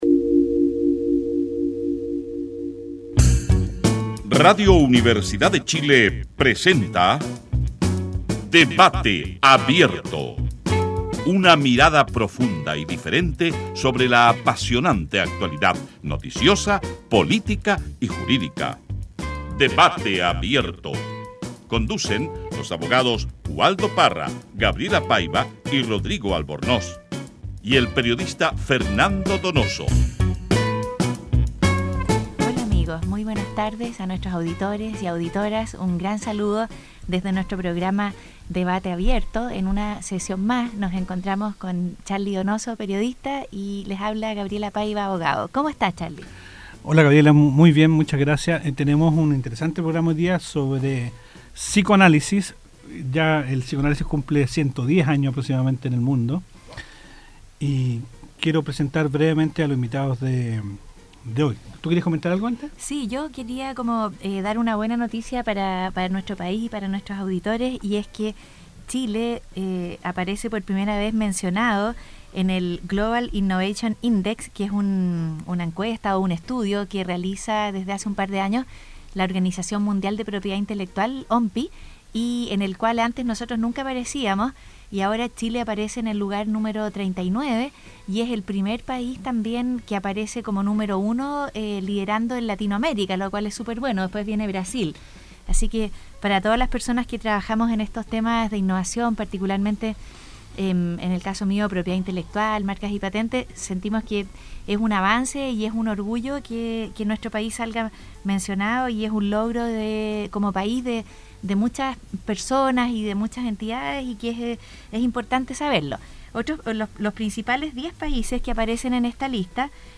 Entrevista realizada en la radio Universidad de Chile el 12 de julio del 2012.